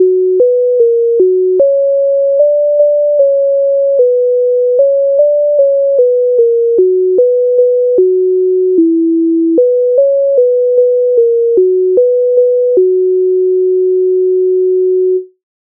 MIDI файл завантажено в тональності h-moll
Мала мати одну дочку Українська народна пісня з обробок Леонтовича с,117
Ukrainska_narodna_pisnia_Mala_maty_odnu_dochku.mp3